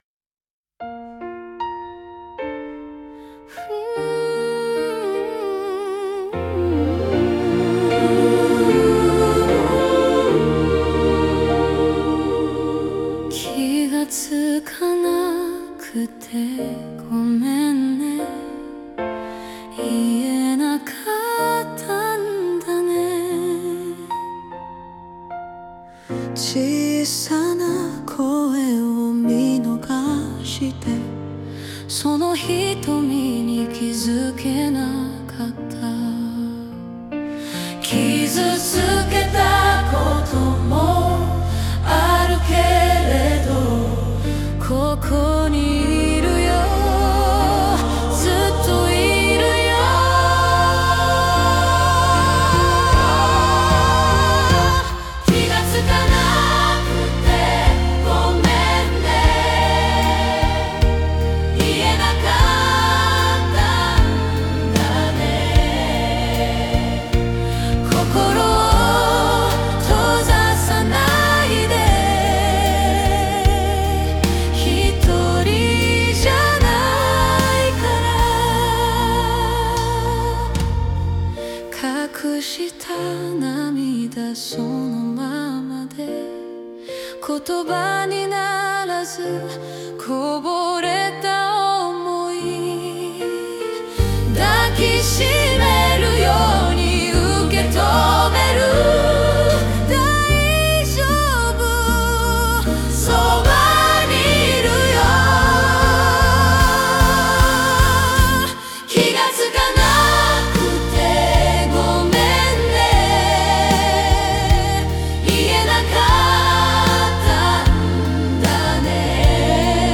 言えなかったんだね ゴスペル曲 - ライブハウス55 自由が丘